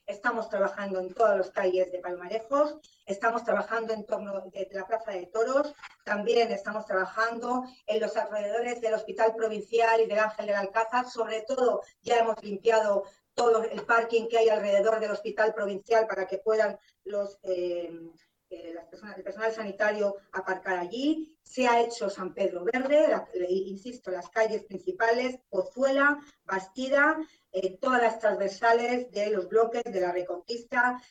La alcaldesa de Toledo, Milagros Tolón, ha comparecido este martes para dar a conocer las últimas informaciones de las que dispone sobre el dispositivo que trabaja para paliar los efectos de la borrasca Filomena a su paso por la ciudad así como el estado de los diferentes servicios e infraestructuras municipales.